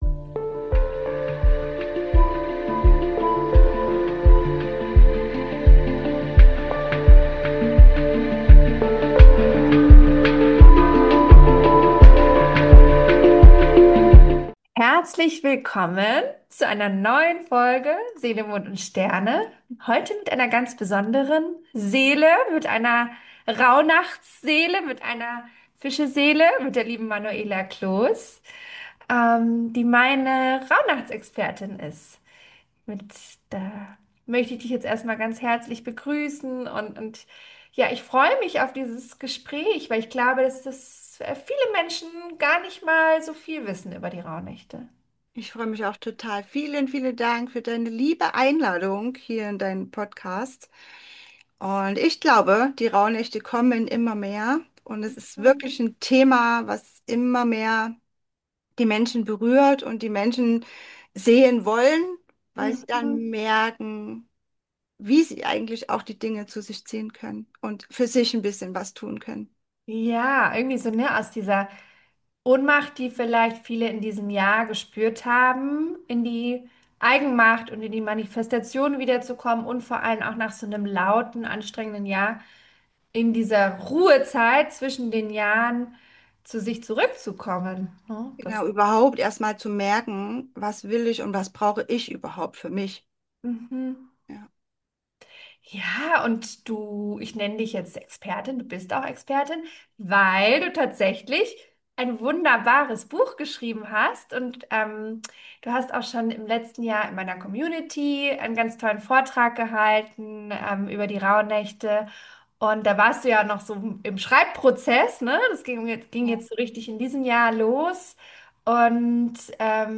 Die_Magie_der_Rauhna--chteInterview.mp3